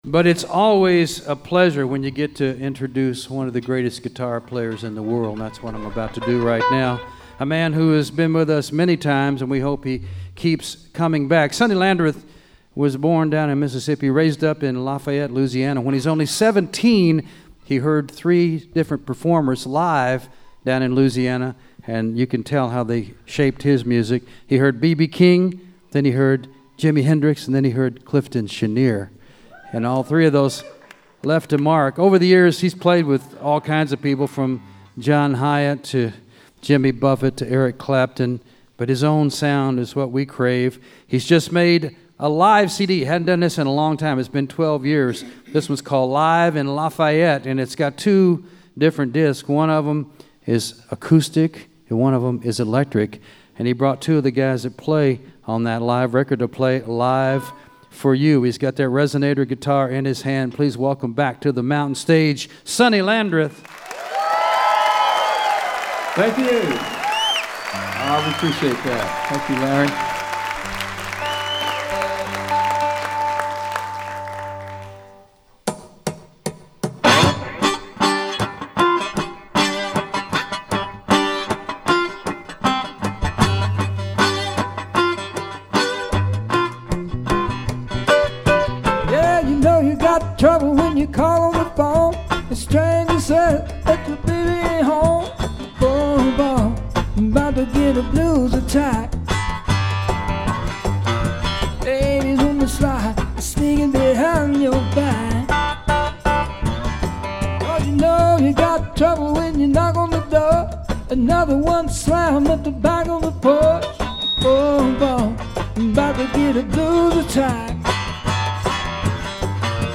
first fully acoustic set
ukulele bass
cajon and percussion